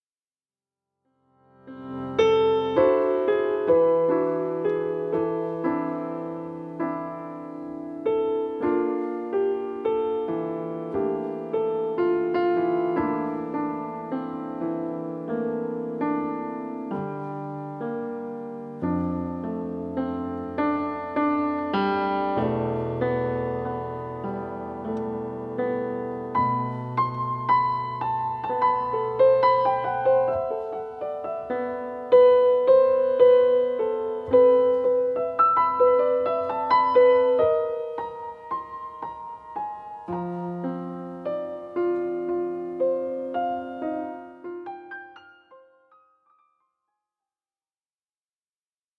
Entre grégorien et gospel, le piano chante Marie.